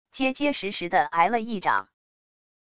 结结实实的挨了一掌wav音频